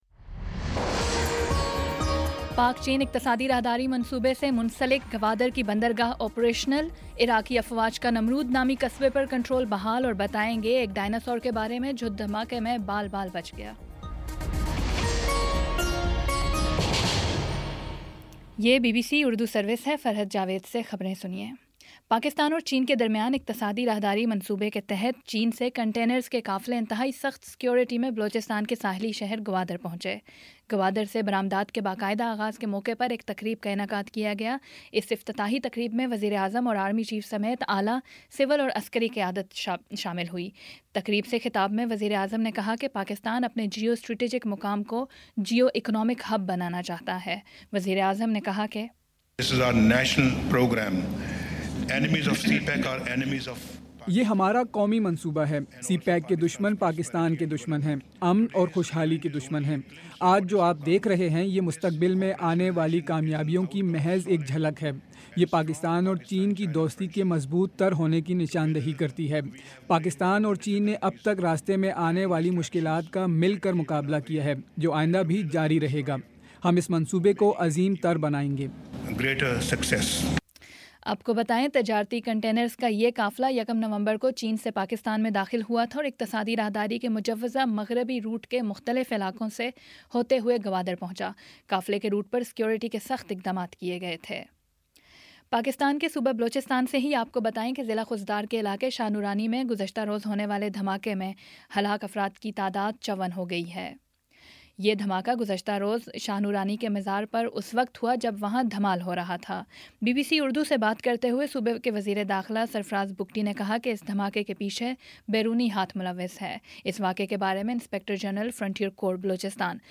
نومبر 13 : شام چھ بجے کا نیوز بُلیٹن